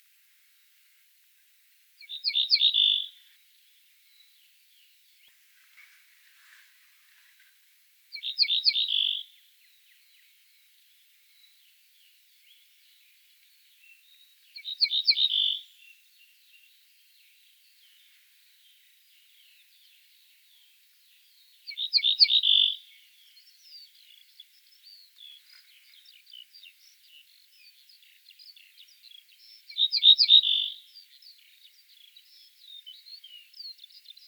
Emberiza hortulana - Ortolan bunting - Ortolano
DATE/TIME: 4/may/2014 (6 p.m.) - IDENTIFICATION AND BEHAVIOUR: The bird is perched (in sight) on the top of a small bush in a steppic hill surrounded by low land with cultivated fields and pastures. - POSITION: Near Izvoarele, Tulcea district, Romania, LAT.N 45°02'/LONG.E 28°35' - ALTITUDE: +150 m. - VOCALIZATION TYPE: full song. - SEX/AGE: adult male - COMMENT: Note the monotonous repetition of only one phrase type. Background: Alaudidae. - MIC: (P)